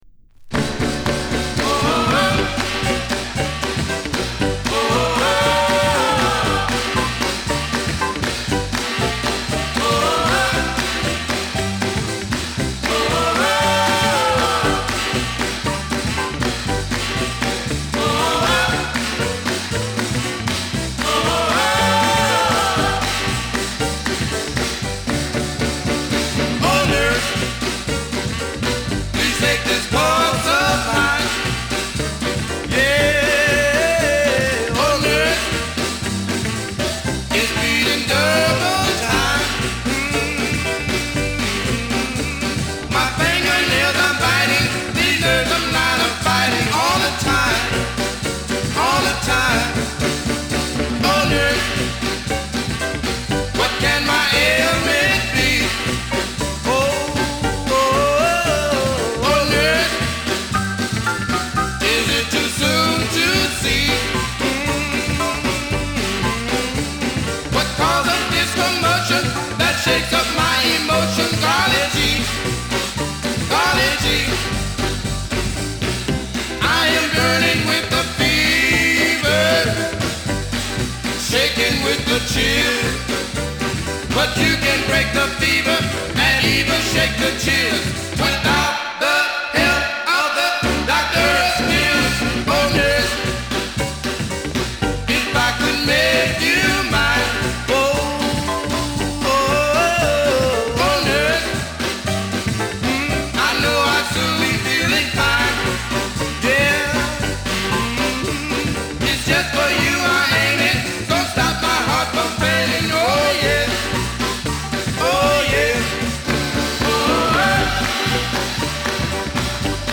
LAスタイル直系のノヴェルティ色濃いファストなR&Bグループ・ロッカー。
陽気でガヤガヤした雰囲気が演奏からダイレクトに伝わってきて気持ちいい。